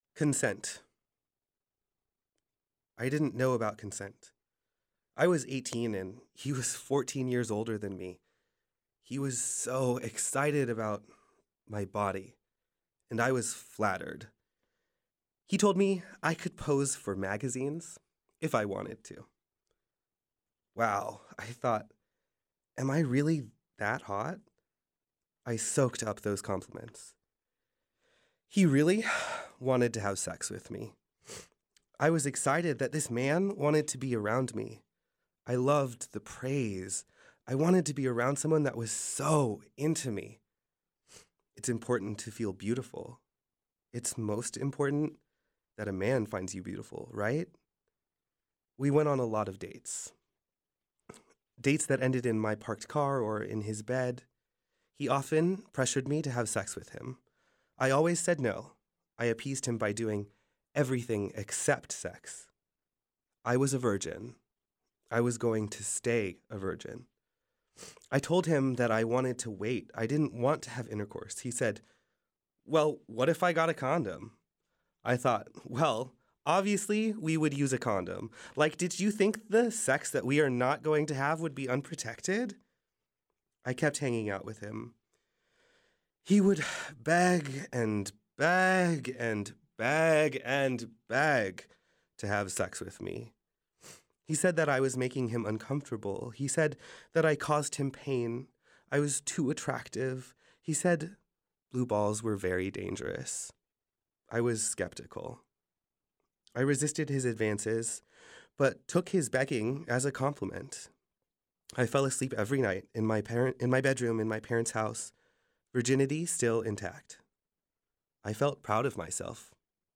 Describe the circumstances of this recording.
This version has been edited for explicit language, but does contain descriptions of sexual assault.